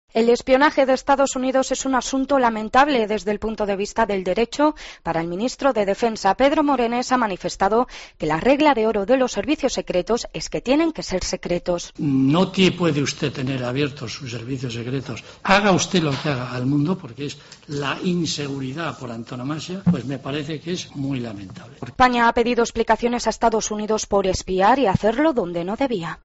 AUDIO: Lo ha dicho en el Congreso el ministro de Defensa, Pedro Morenés. La crónica, desde la cámara baja